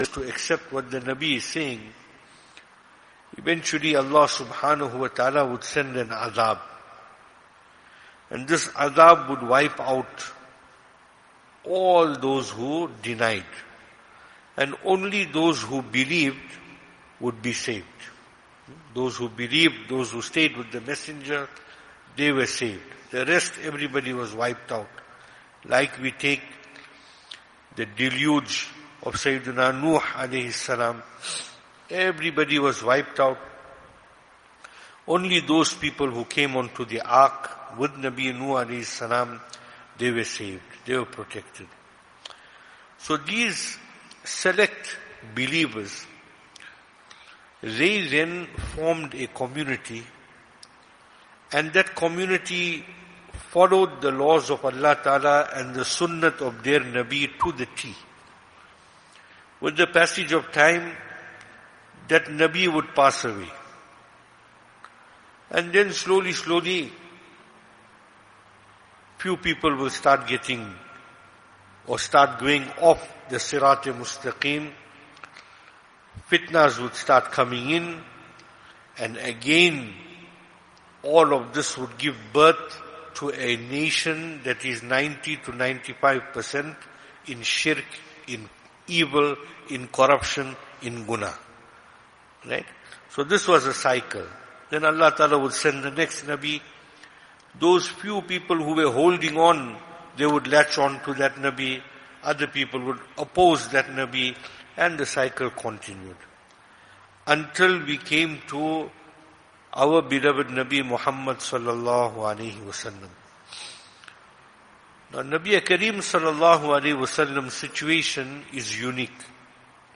Venue: siratul Jannah Service Type: Ladies Program